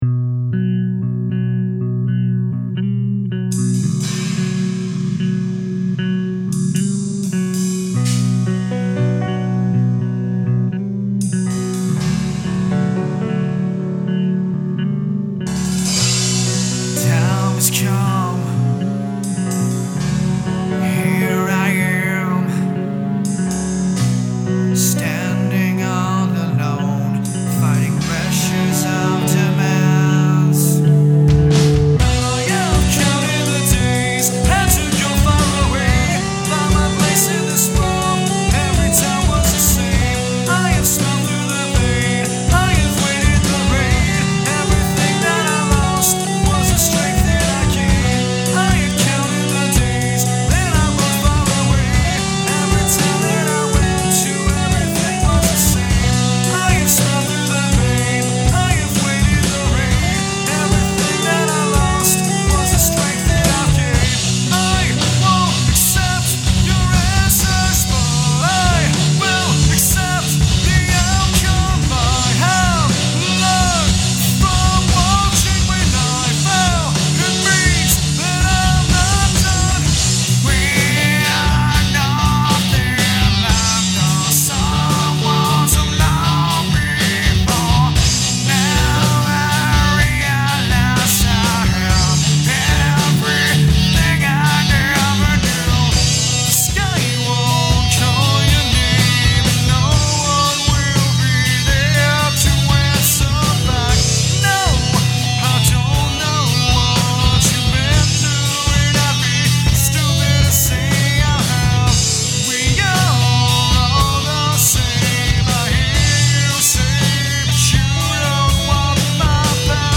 We're mostly hard rock, but this is more of our 'artistic departure from the norm' song.
Shure SM57 on snare, CAD PRO-7 drum kit mics for the rest. 2 SM57's on bass, recorded into a single stereo track, MXL 990 for vocals, and the SM57 along with a Nady RSM-5 ribbon mic for the guitar. I used Slate triggers on the kick and snare in parallel with the actual tracks (kick needed a bit more boom, snare needed more 'crack').
I added strings to taste. I can actually play piano, but I just used midi for the strings and piano track.
I'm the bass player which is why the bass really carries the theme of the song. The arrangement is kind of a pyramid, it starts soft, peaks in the middle, and ends soft.